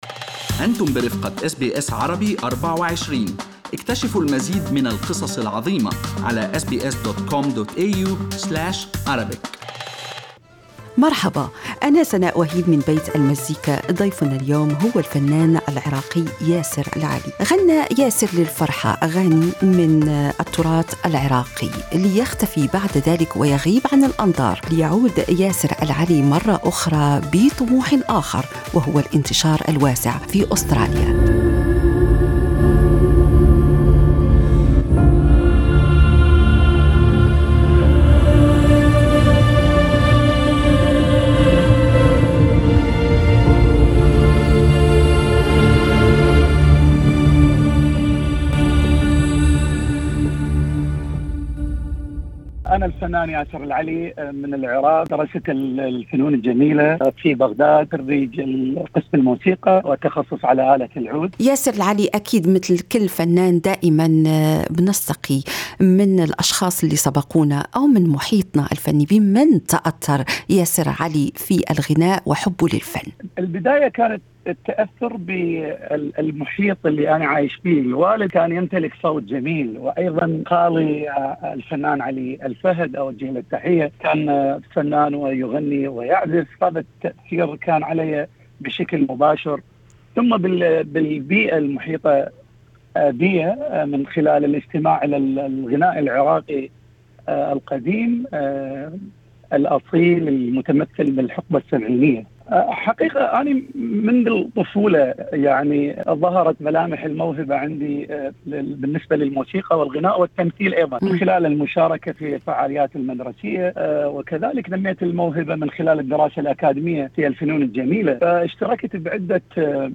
يستضيف بيت المزيكا في حلقته الأسبوعية الفنان العراقي